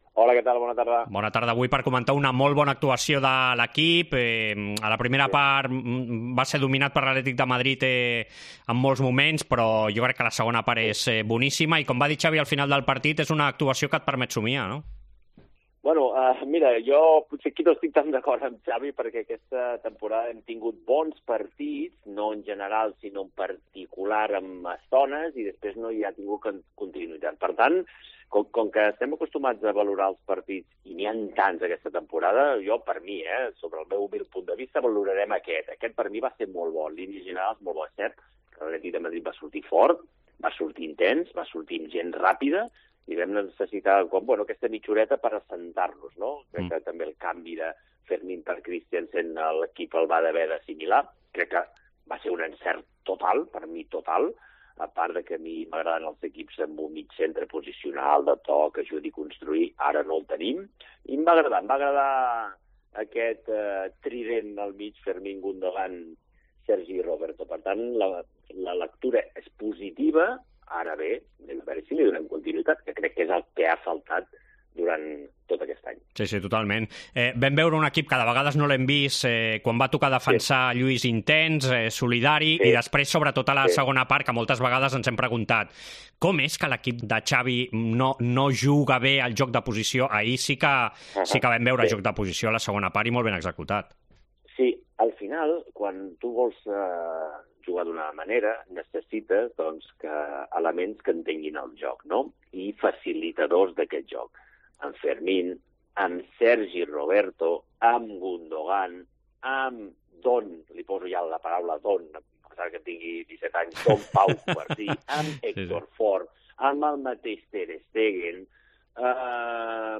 Análisis en Esports COPE